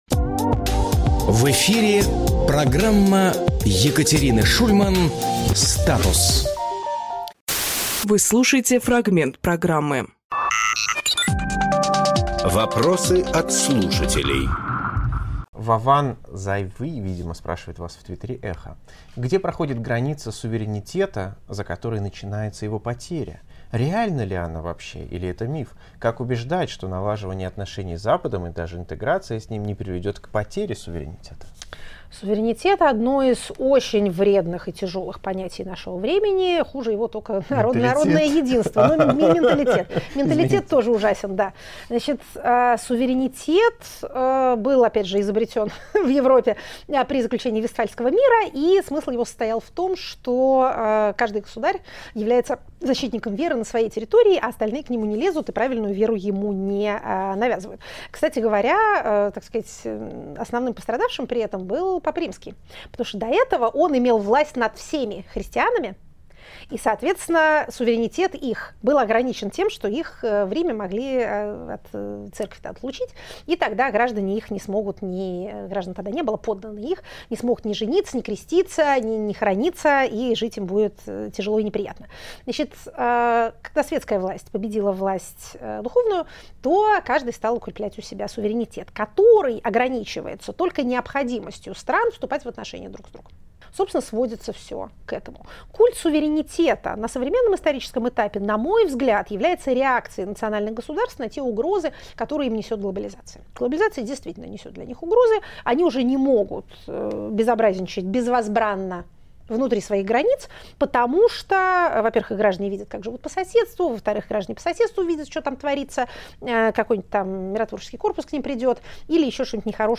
Екатерина Шульманполитолог
Фрагмент эфира от 21.05.24